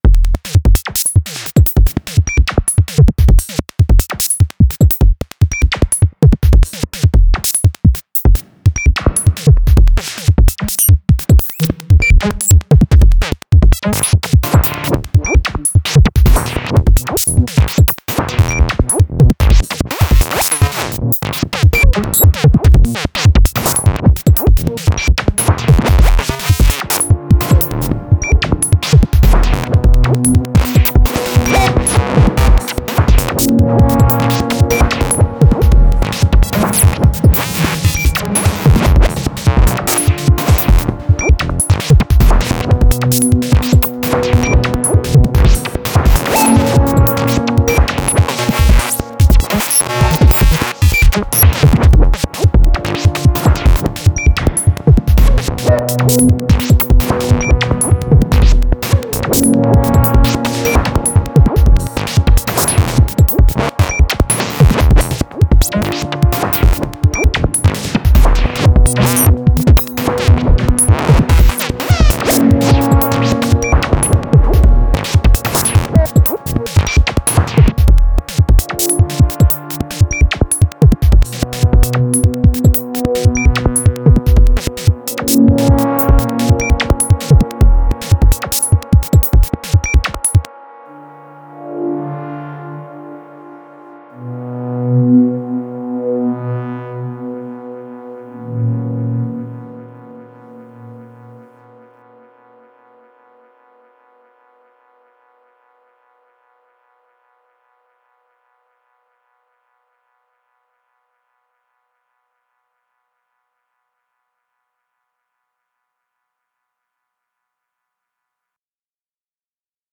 A4 mk1, single take, nuffink else. it’s a couple of years old tbh.